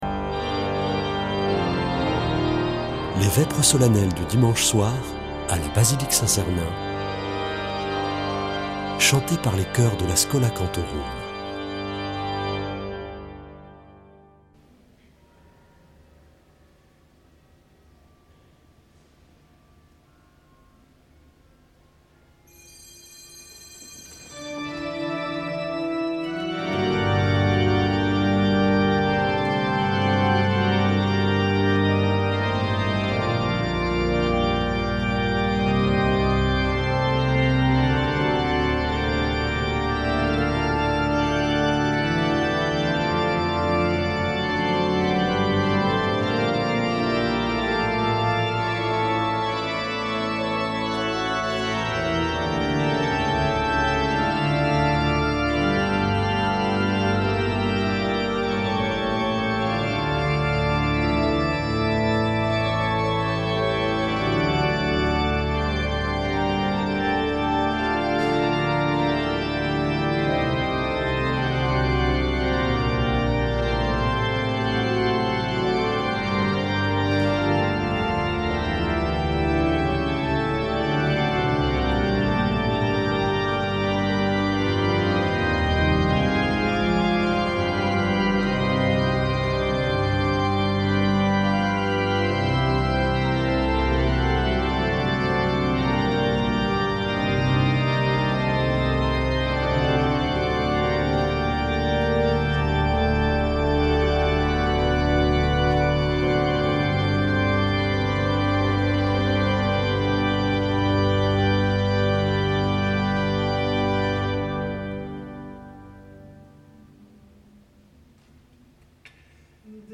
Vêpres de Saint Sernin du 15 sept.
Une émission présentée par Schola Saint Sernin Chanteurs